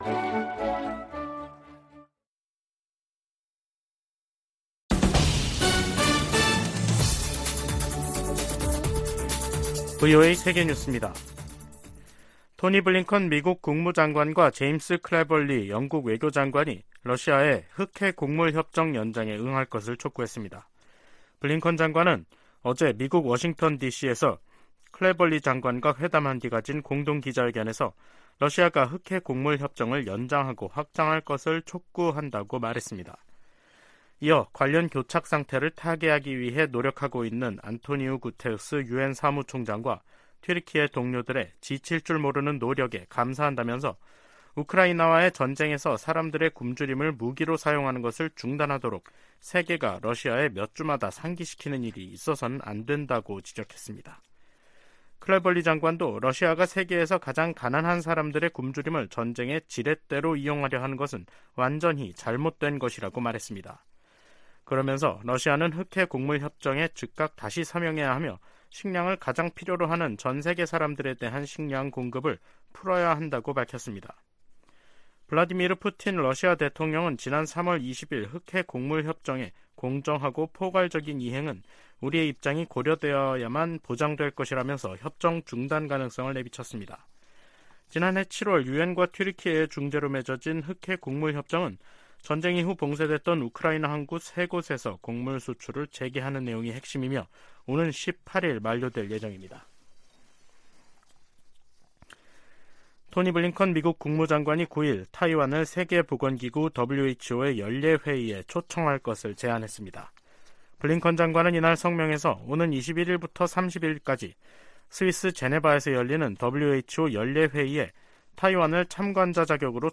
VOA 한국어 간판 뉴스 프로그램 '뉴스 투데이', 2023년 5월 10일 3부 방송입니다. 미 국무부는 ‘미한일 미사일 정보 실시간 공유 방침’ 관련 일본 언론 보도에 대해, 비공개 외교 대화 내용을 밝히지 않는다면서도, 3각 공조는 필수라고 강조했습니다. 미한일의 미사일 정보 실시간 공유가 공조·대응 체제 구축에 큰 도움이 될 것이라고 미국 전문가들이 평가했습니다.미 북부사령관은 북한의 위협을 거론하며 차세대 요격기 배치의 중요성을 강조했습니다.